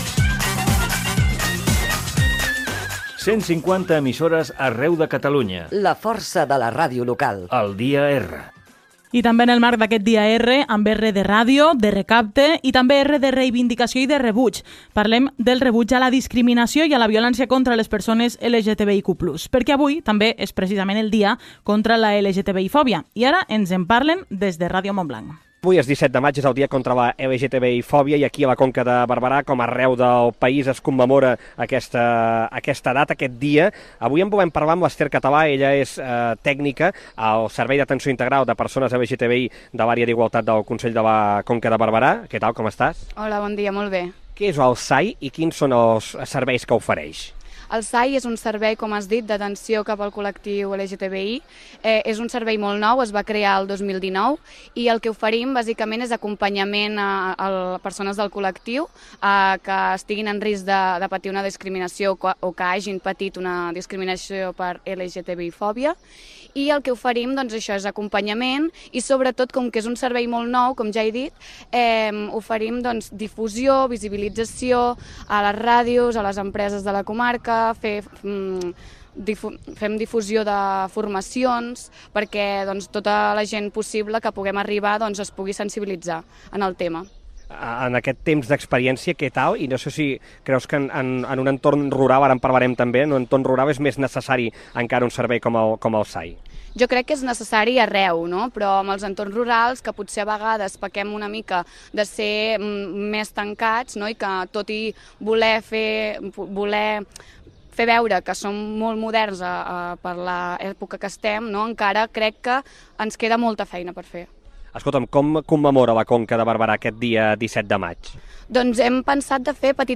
Indicatiu del programa, entrevista
Divulgació